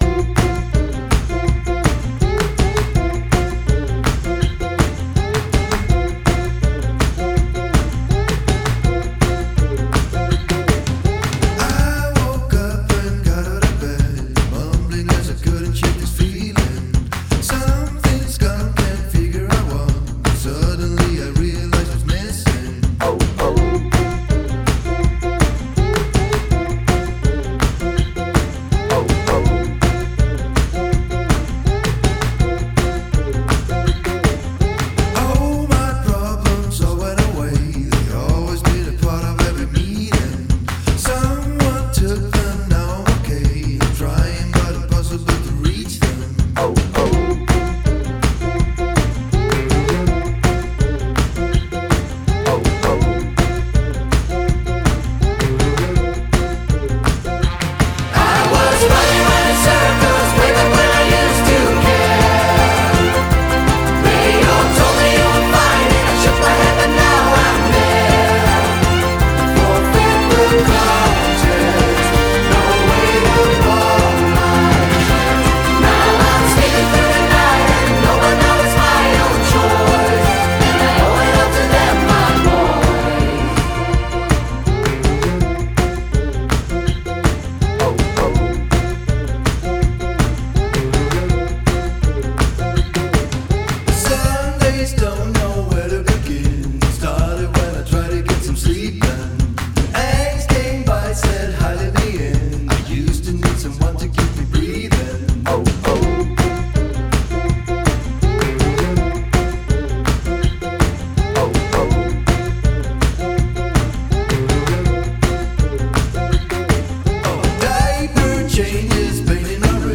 Genre: Indie-Pop / Folk